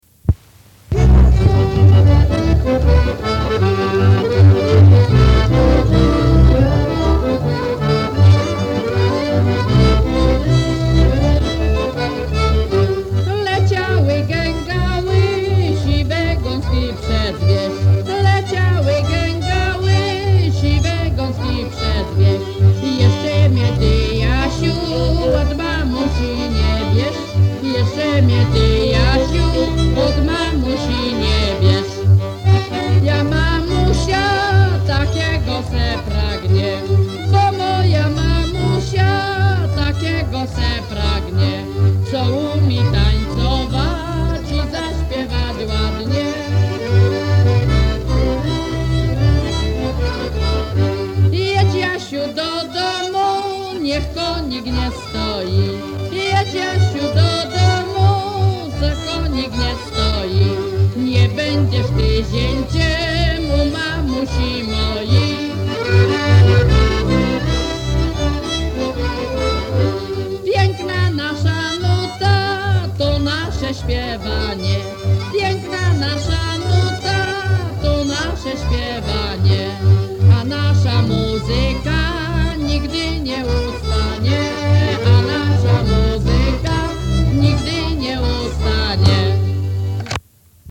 Leciały, gęgały, siwe gąski przez wieś – Żeńska Kapela Ludowa Zagłębianki
Nagranie archiwalne